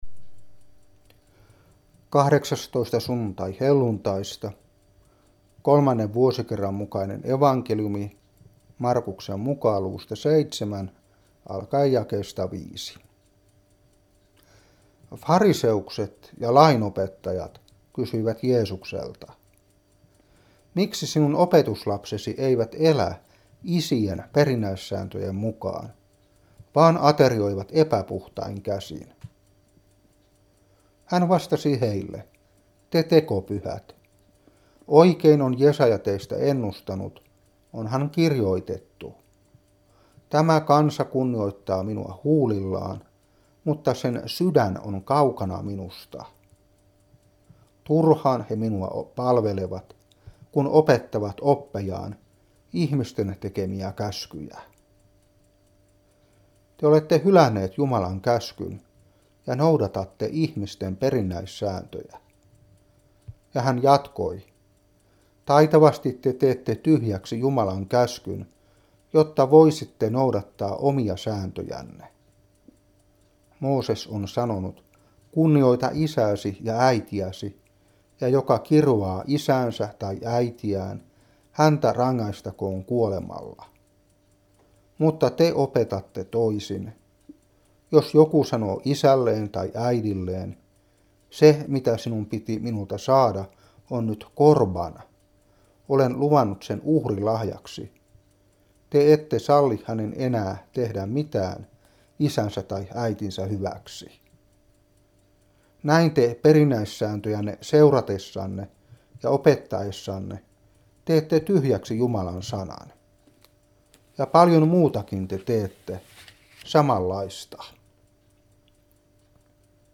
Saarna 2015-9. Mark.7:5-13.